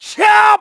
KaselB-Vox_Attack3_kr.wav